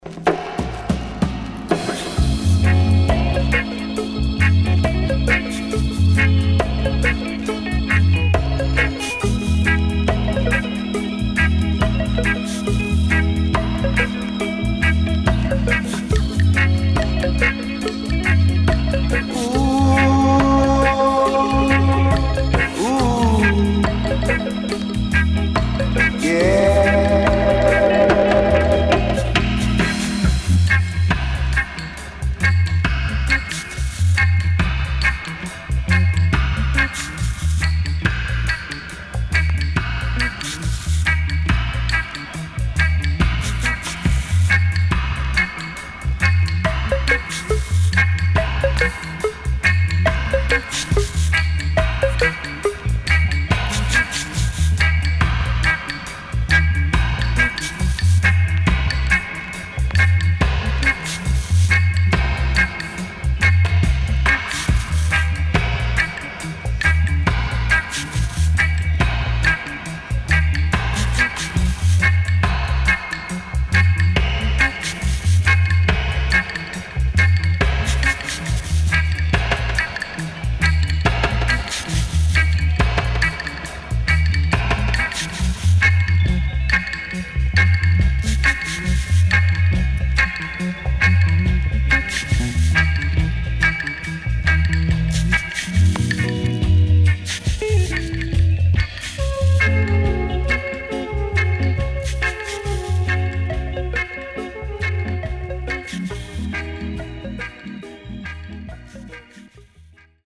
CONDITION SIDE A:VG+〜EX-
SIDE A:プレス起因で少しノイズ入ります。